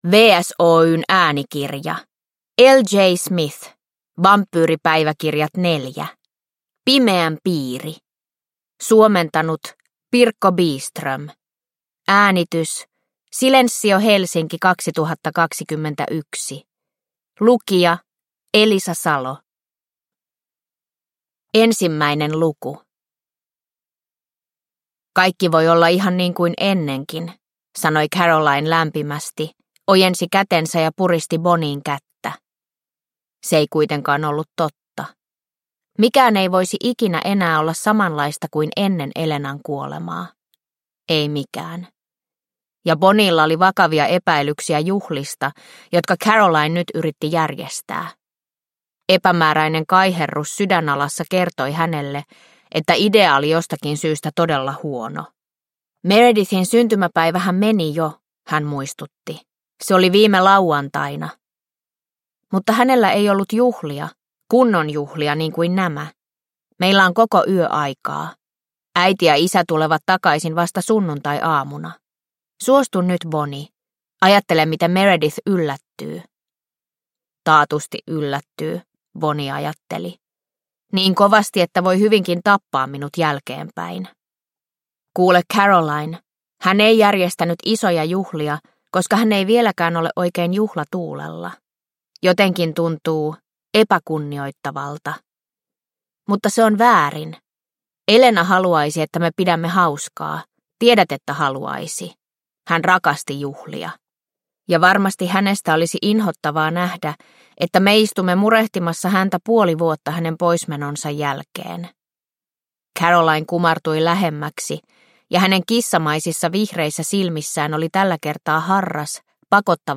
Pimeän piiri – Ljudbok – Laddas ner